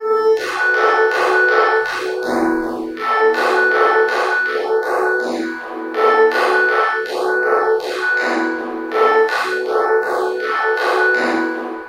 描述：卧室的门快速而用力地关闭，几乎是砰的一声。
声道立体声